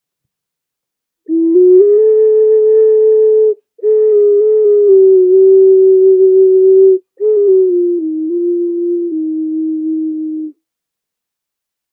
Snapping Turtle Ocarina Ancient Flutes
This Instrument produces a lovely melody.
A recording of the sound of this particular ocarina is in the top description, just click on the play icon to hear the sound.
It weighs almost 2 pounds has 4 key holes. Key of D pentatonic scale